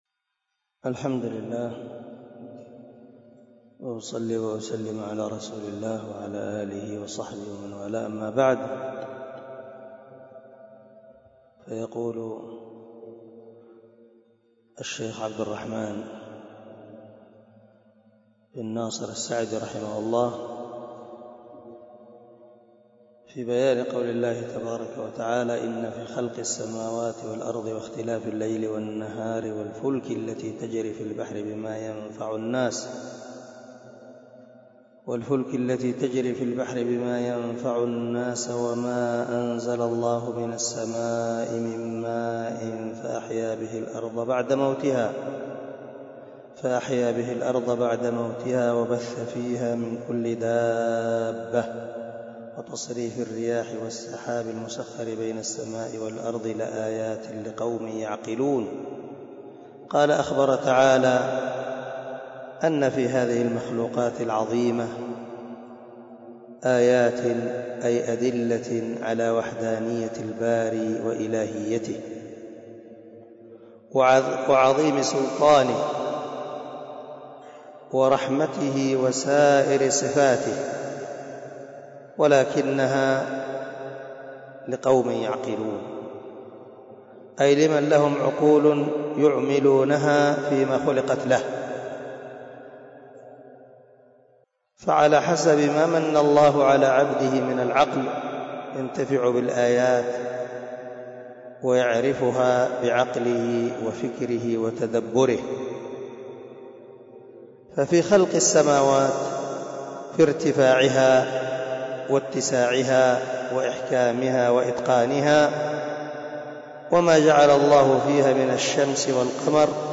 071الدرس 61 تابع تفسير آية ( 164 ) من سورة البقرة من تفسير القران الكريم مع قراءة لتفسير السعدي